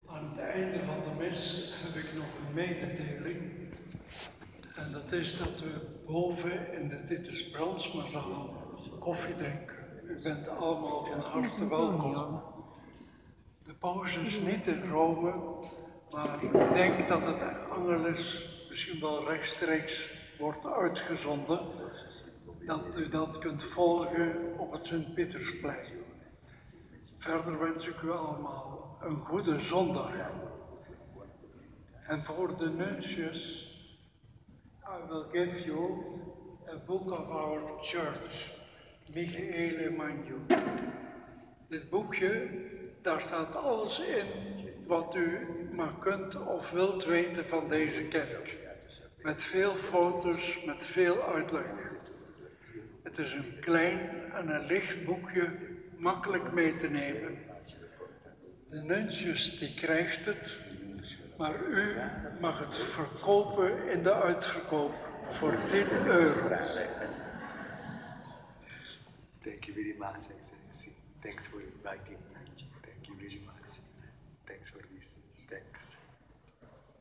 Viering 16 juni 2019, Drievuldigheidszondag
slotwoord Mgr Antoon Hurkmans
Slotwoord.mp3